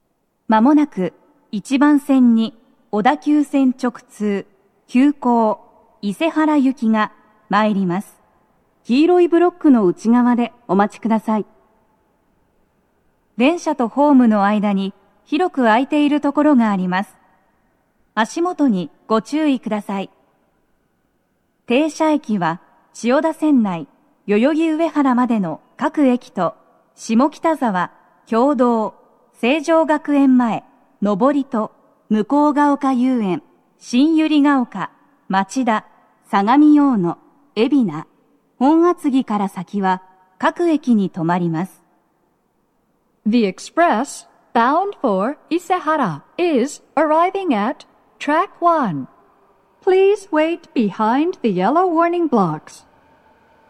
鳴動は、やや遅めです。鳴動中に入線してくる場合もあります。
接近放送7